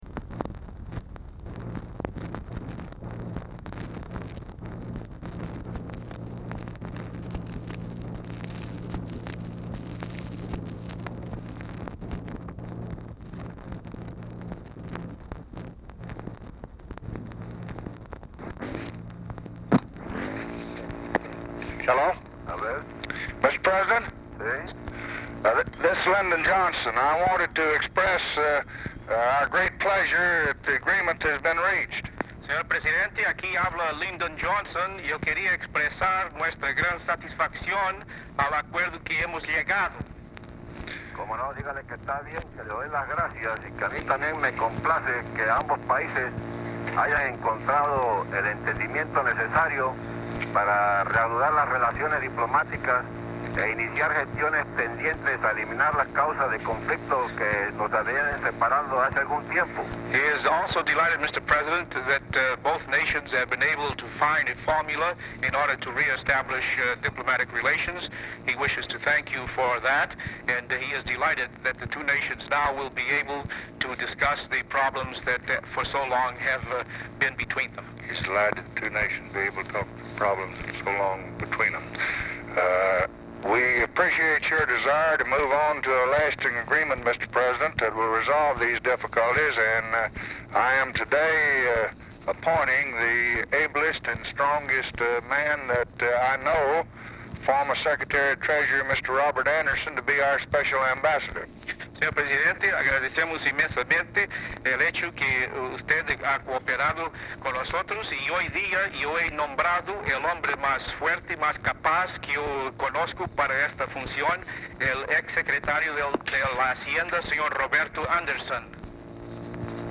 Telephone conversation
CHIARI CLEARER IN THIS RECORDING; HUMMING NOISE IN BACKGROUND
Format Dictation belt
Other Speaker(s) TRANSLATORS